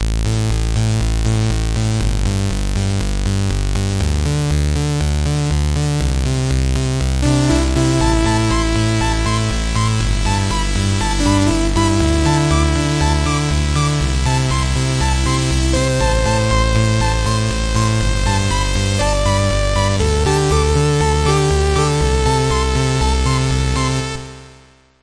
Synth: smalltown
synth-smalltown.mp3